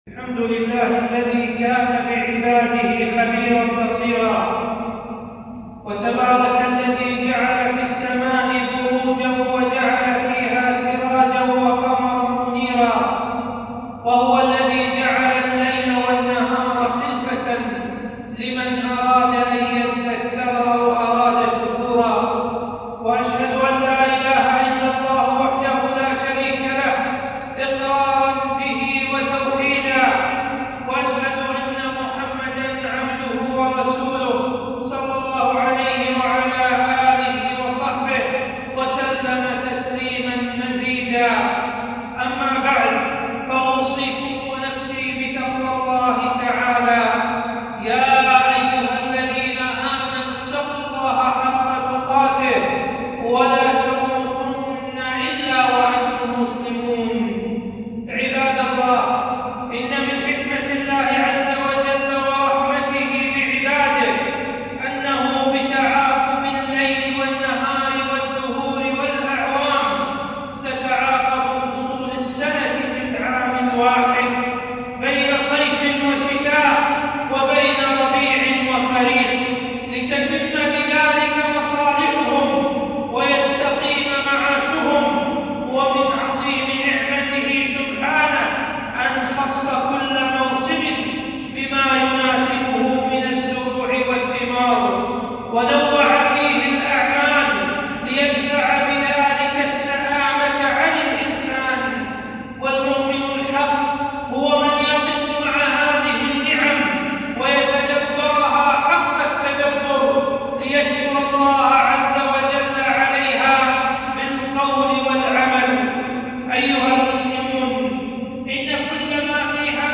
خطبة - أحكام الشتاء ج1